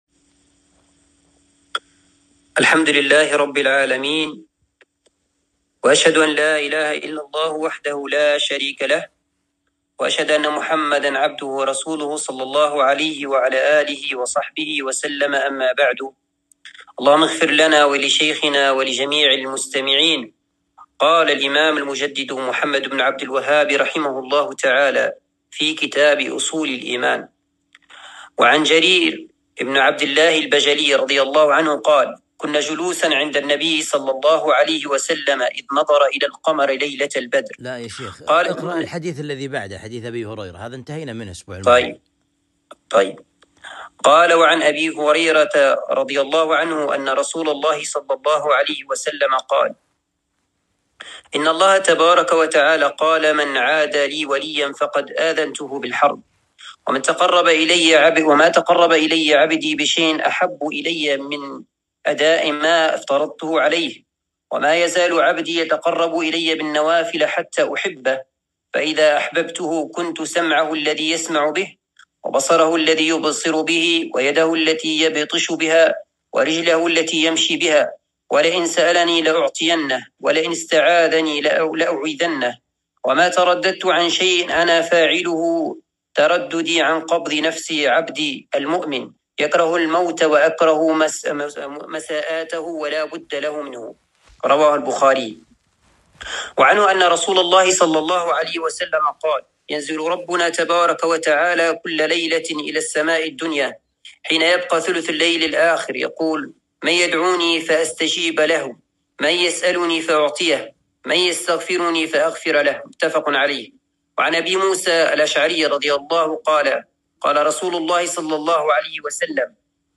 الدرس الثاني من كتاب أصول الإيمان